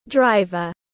• Driver /ˈdraɪvər/: người lái xe, tài xế